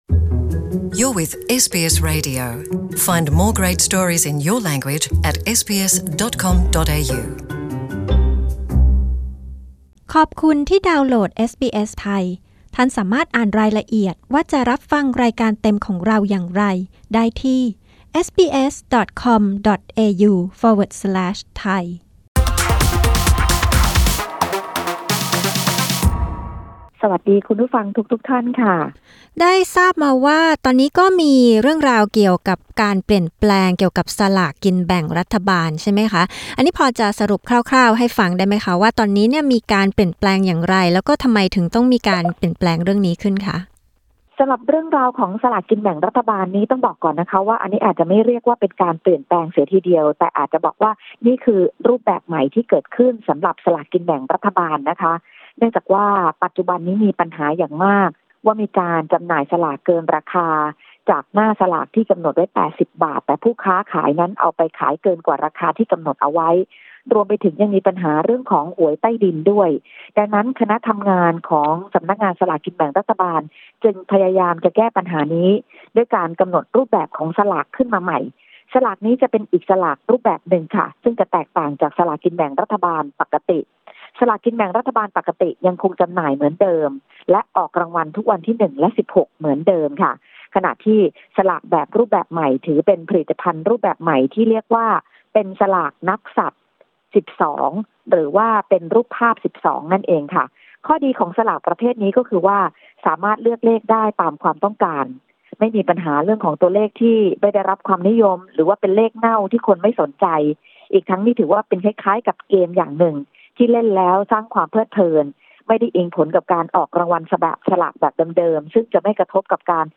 กดปุ่ม (▶) ด้านบนเพื่อฟังรายงานข่าวสายตรงจากเมืองไทย